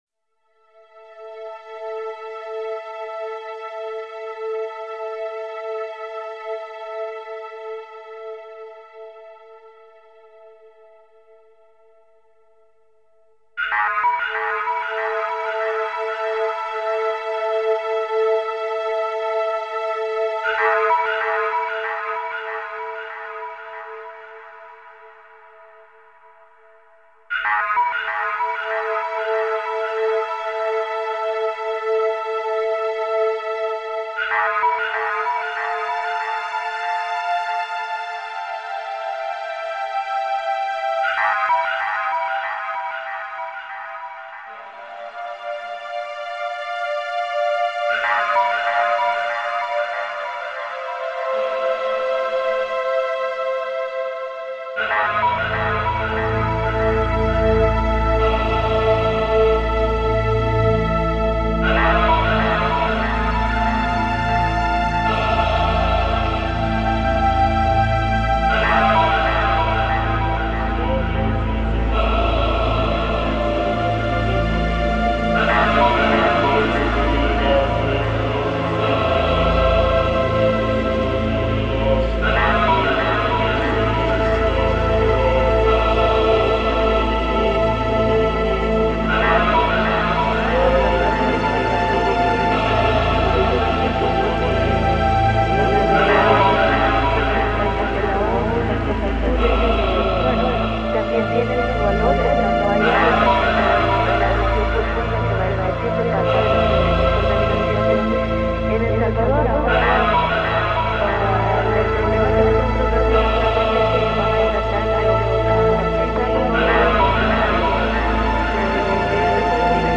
Ambient Эмбиент